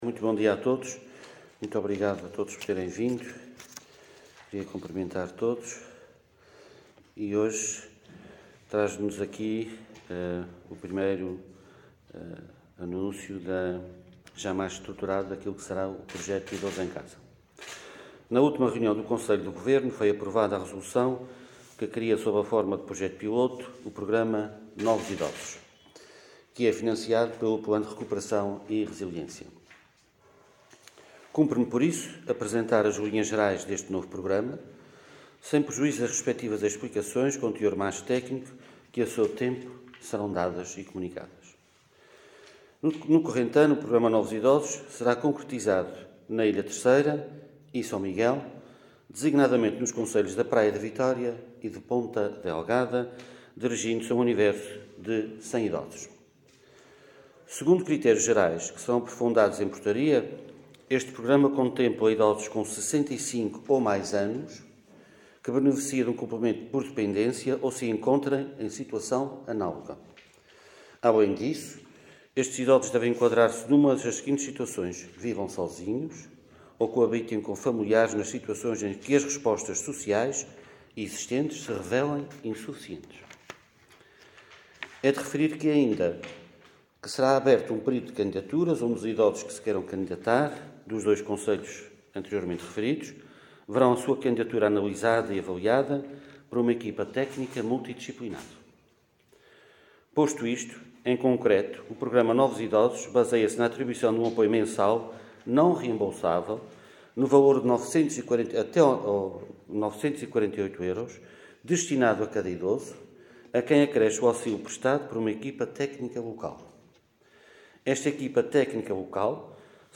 O governante falava numa conferência de imprensa, em Angra do Heroísmo, onde apresentou as “linhas gerais” deste novo programa na área social, recentemente aprovado em reunião do Conselho do Governo.